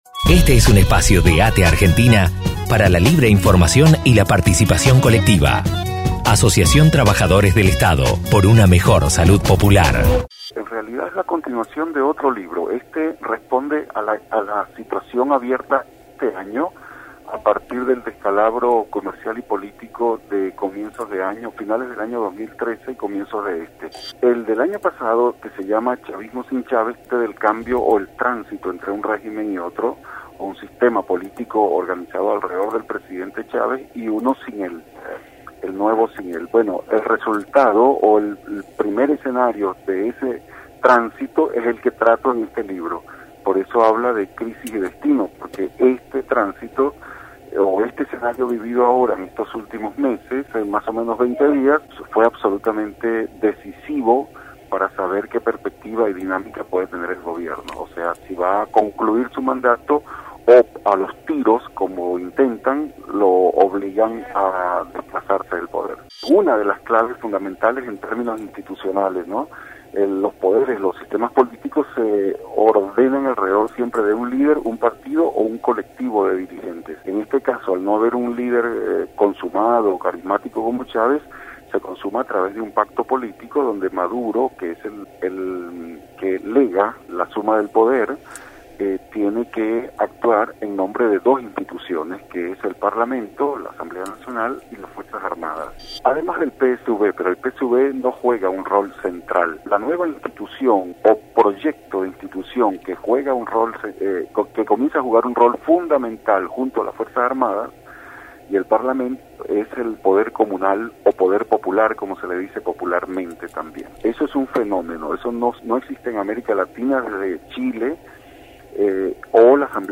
Desde Armar la Trama conversamos con